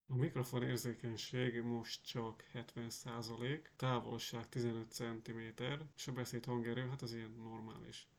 Mikrofon típusa: kondenzátor (kardioid)
Egyszerű (középkategóriás) alaplap jack bemenetére csatlakozva került kipróbálásra némi PC háttérzaj mellett.
A minősége, érzékenysége jó és főleg az a szimpatikus, hogy realisztikusan veszi fel a hangot, tehát nem torzítja el, nem lesz fura hangzása.
Hang minta 70% érzékenységgel